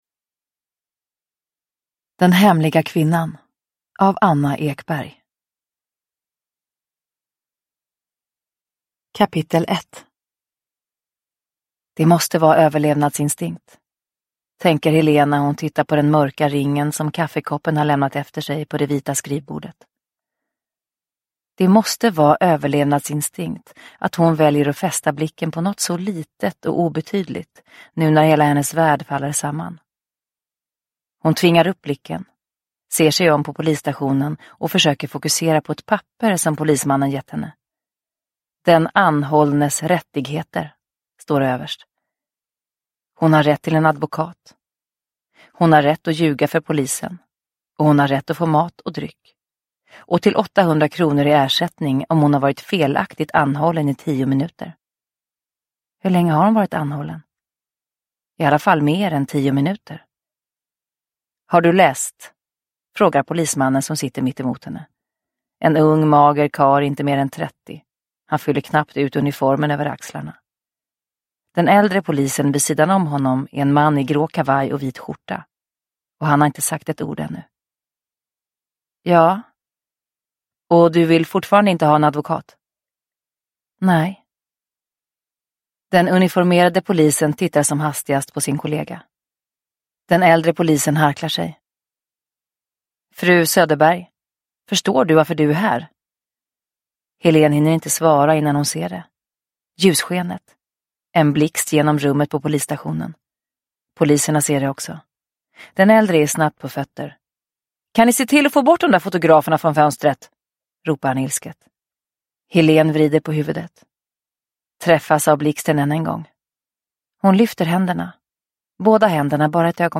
Den hemliga kvinnan – Ljudbok – Laddas ner